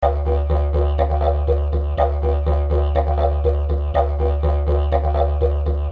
民族大鼓巡游
Tag: 107 bpm Dance Loops Tabla Loops 3.02 MB wav Key : Unknown Cubase